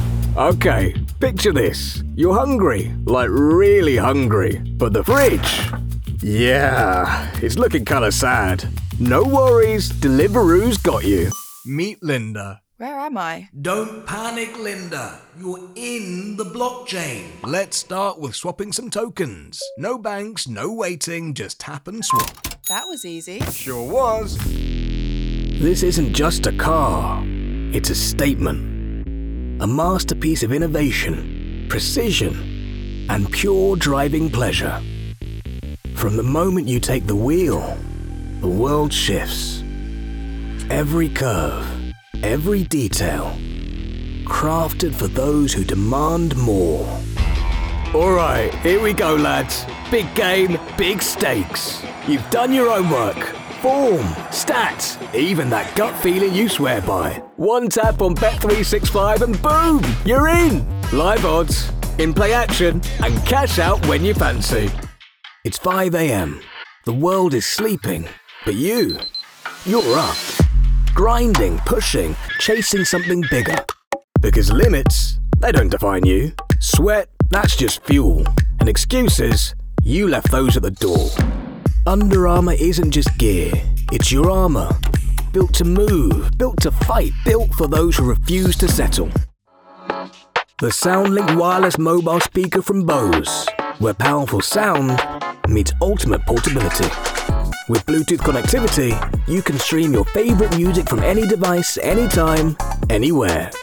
Commercial Showreel
Male
Gravelly
Husky
1-commercial-voicereel.wav